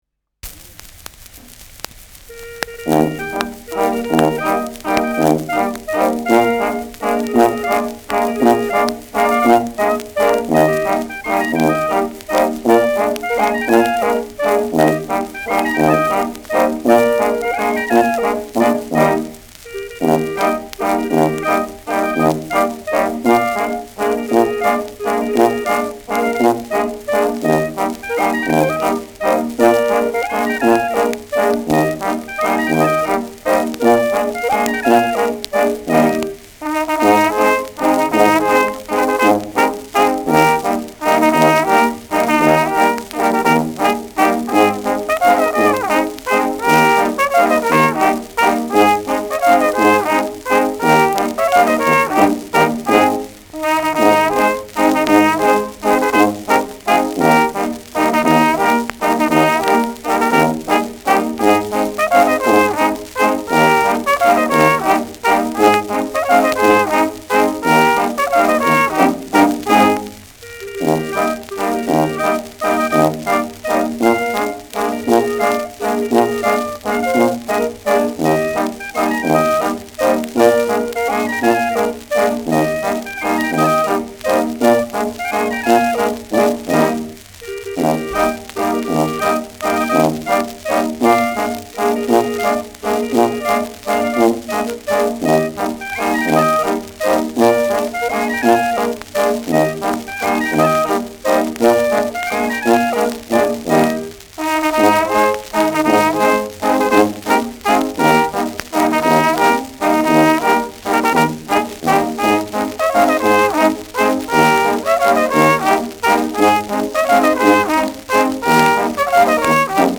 Schellackplatte
präsentes Knistern : leichtes Rauschen : Knacken zu Beginn : gelegentliches Knacken
Kapelle Jais (Interpretation)
Die Kapelle kombiniert den Figurentanz mit einem Zwiefachen, quasi als Nachtanz.